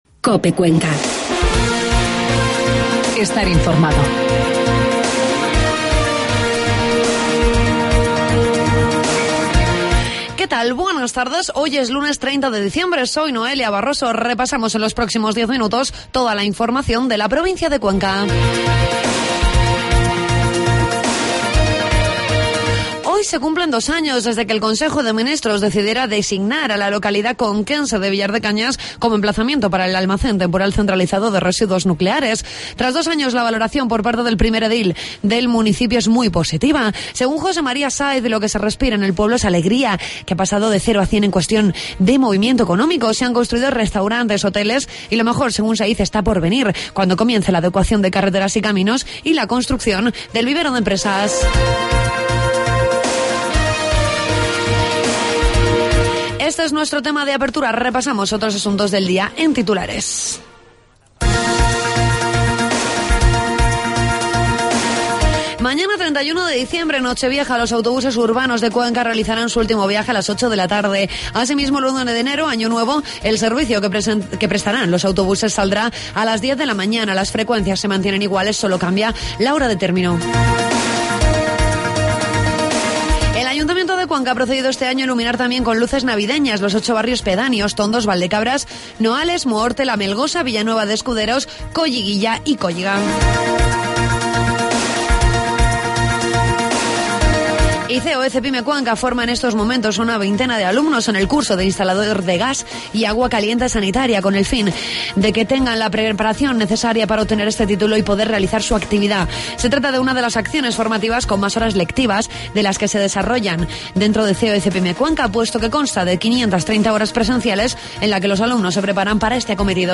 Redacción digital Madrid - Publicado el 30 dic 2013, 14:39 - Actualizado 02 feb 2023, 00:47 1 min lectura Descargar Facebook Twitter Whatsapp Telegram Enviar por email Copiar enlace Toda la información de la provincia de Cuenca en los informativos de mediodía de COPE.